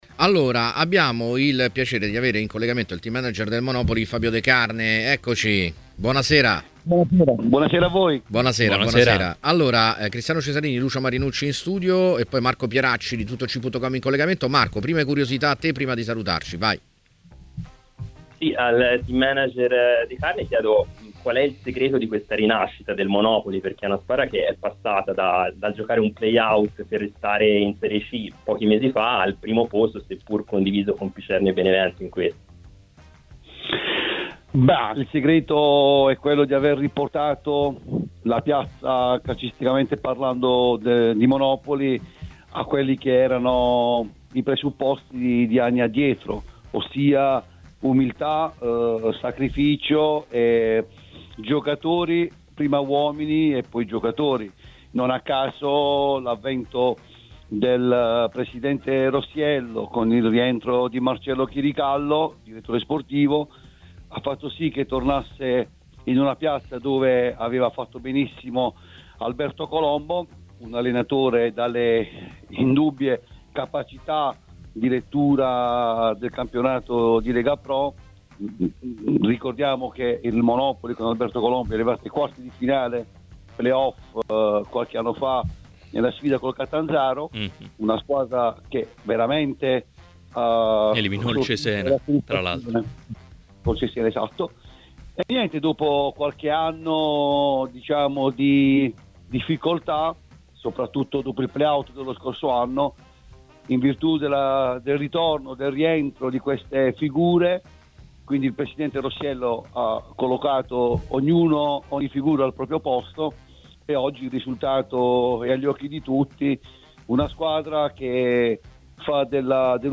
è intervenuto nel corso di “A Tutta C” a TMW Radio per commentare vari temi.